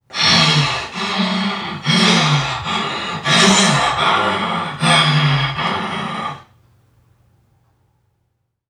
NPC_Creatures_Vocalisations_Robothead [68].wav